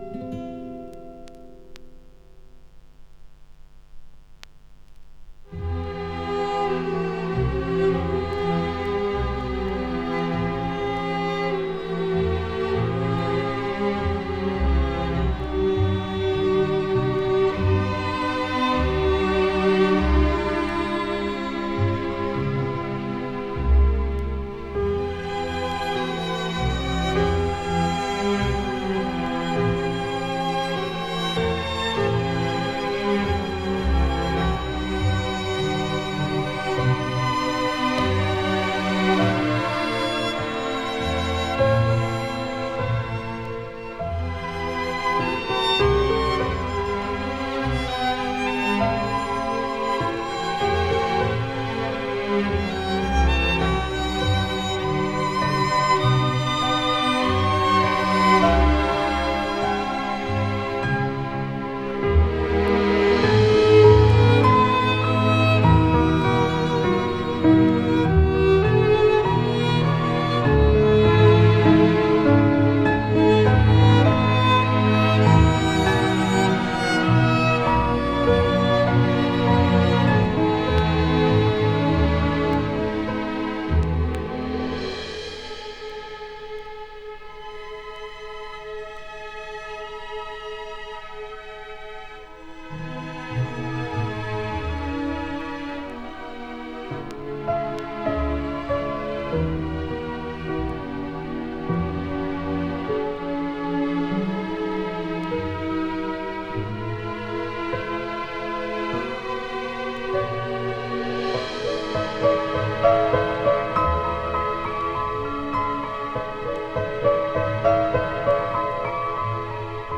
Ze zijn trouwens niet mono, maar stereo.
Er missen gewoon veel te veel hoge tonen.
Daar zit zoveel rumble in.